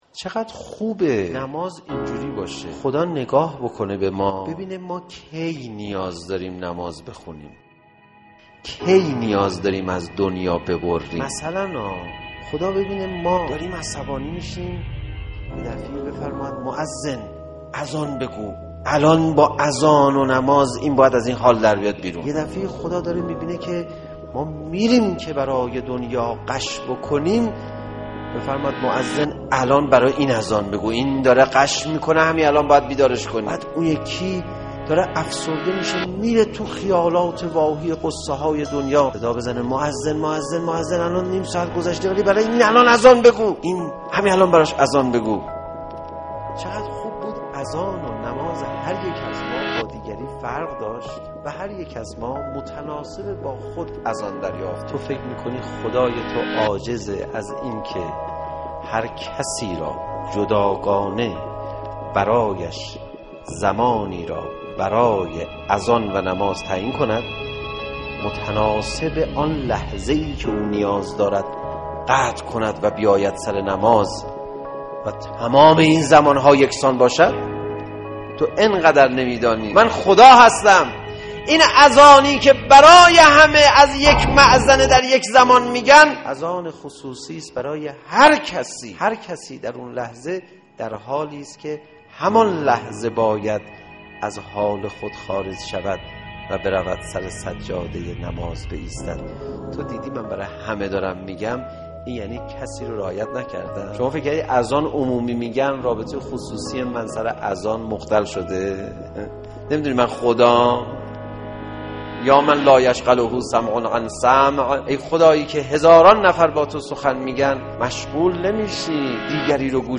azan.mp3